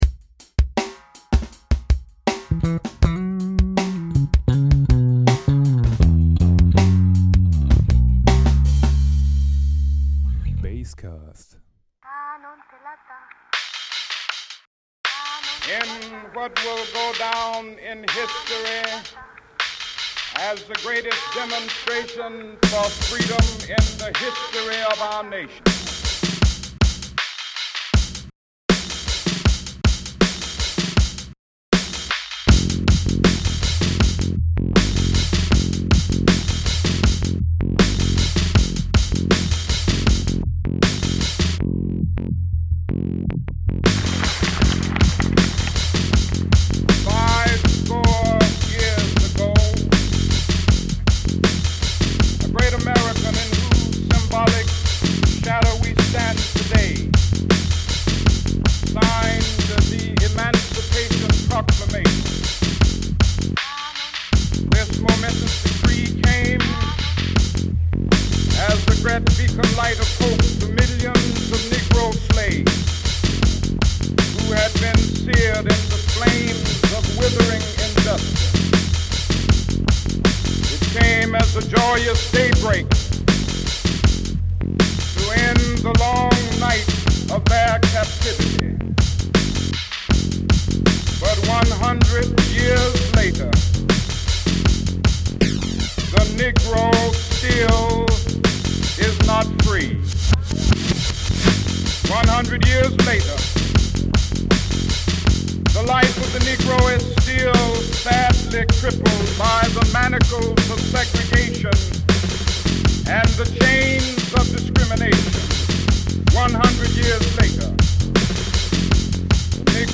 Martin Luther King fitted perfectly on my basswork.